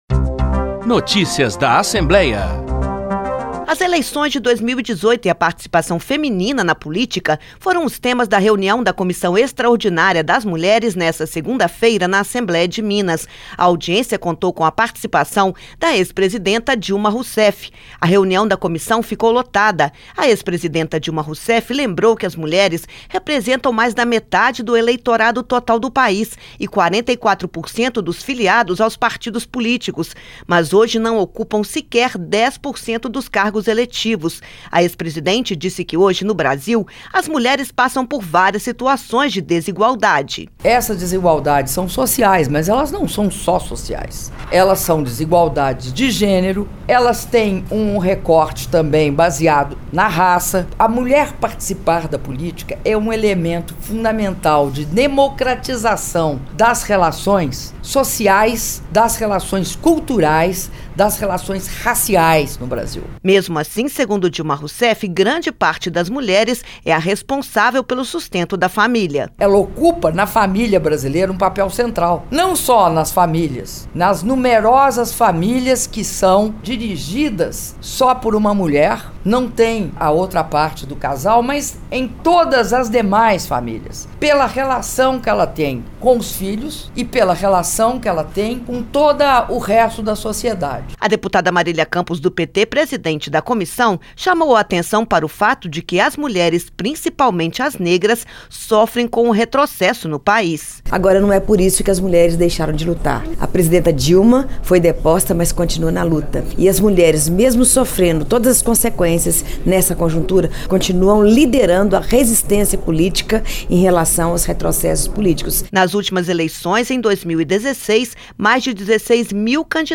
A audiência, na ALMG, contou com a presença da ex-presidenta Dilma Rousseff.